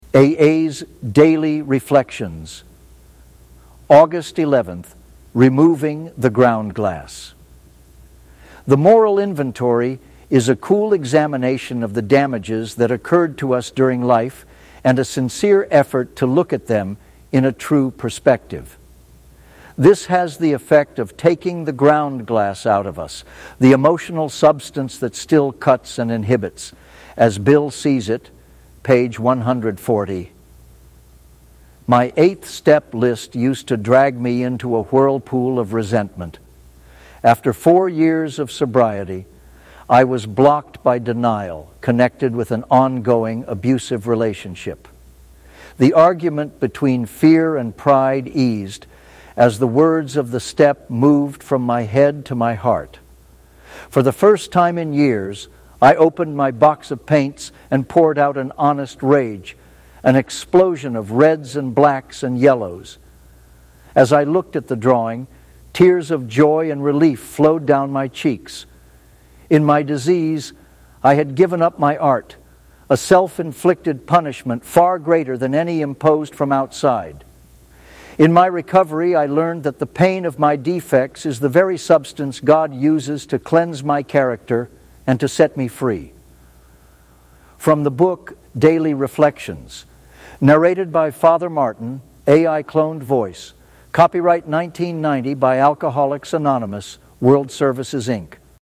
Cloned Voice.